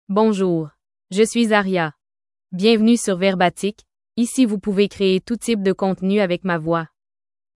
FemaleFrench (Canada)
Aria is a female AI voice for French (Canada).
Voice sample
Aria delivers clear pronunciation with authentic Canada French intonation, making your content sound professionally produced.